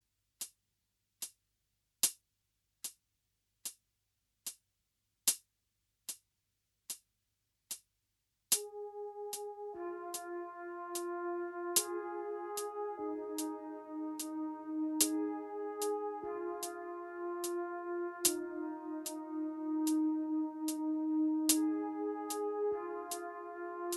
Minus All Guitars Pop (2010s) 4:20 Buy £1.50